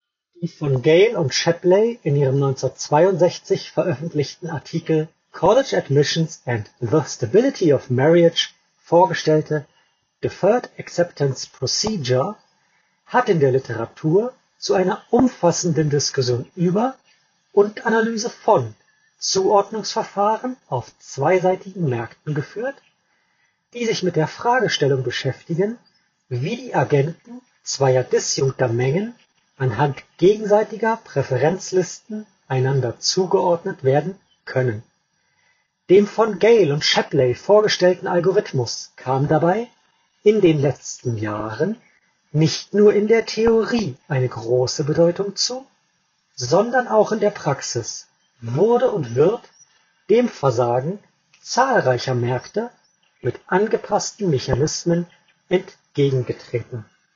Google Pixel Buds Pro 2 – Mikrofonqualität